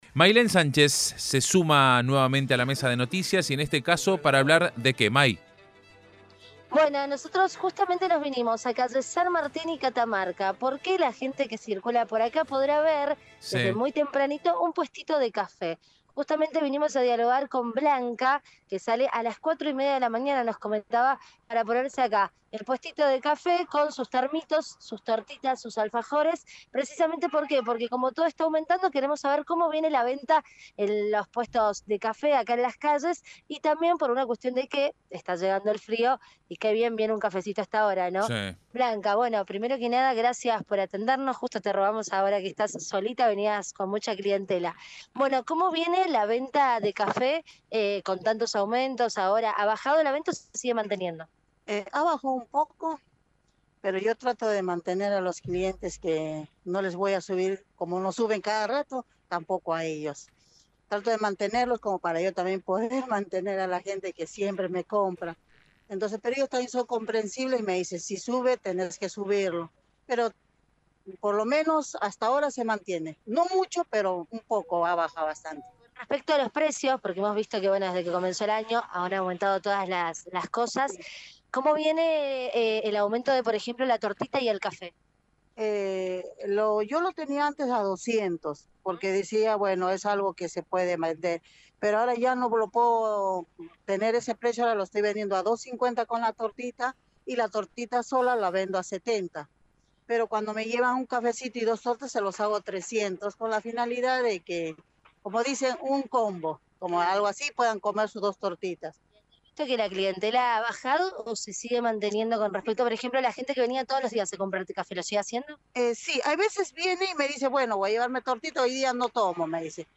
LVDiez - Radio de Cuyo - Móvil de LVDiez desde puesto café de San Martin y Catamarca, Cdad